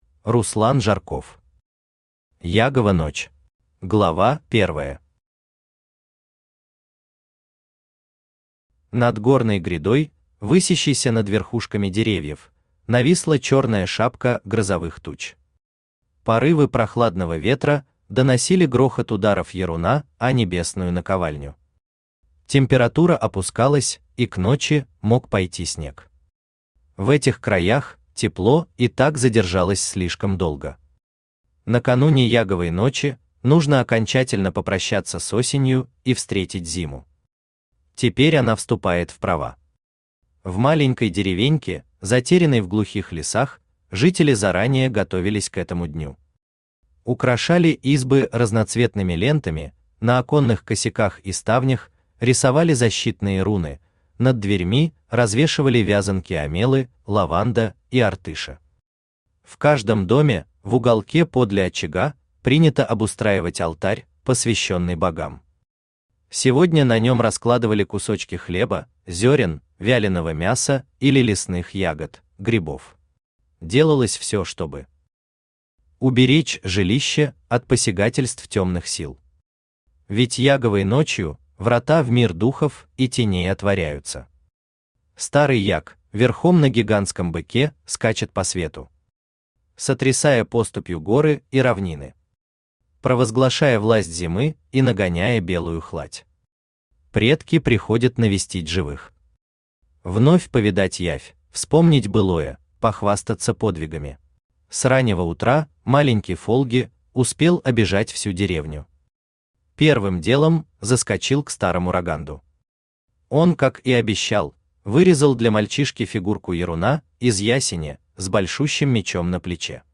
Аудиокнига Ягова ночь | Библиотека аудиокниг
Aудиокнига Ягова ночь Автор Руслан Андреевич Жарков Читает аудиокнигу Авточтец ЛитРес.